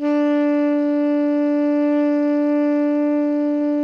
TENOR PP-D4.wav